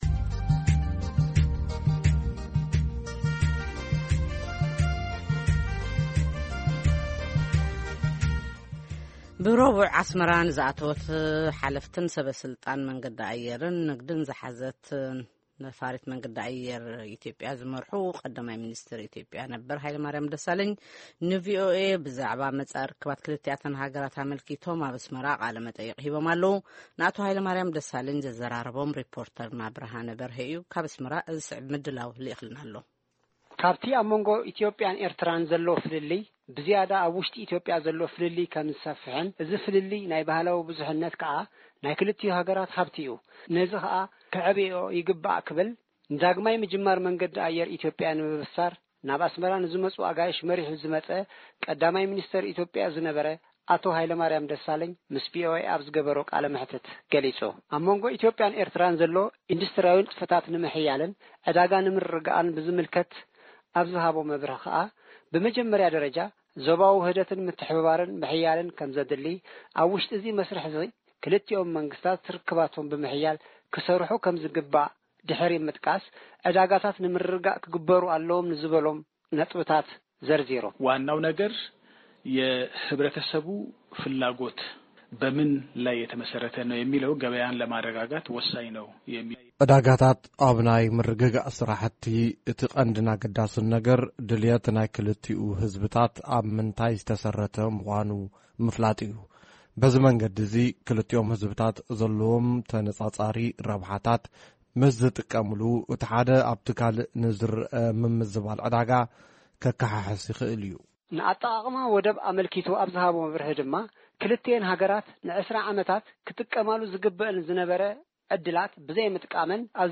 ቃለ መጠይቅ ምስ ቀ/ሚ ኢትዮጵያ ነበር ሃይለማርያም ደሳለኝ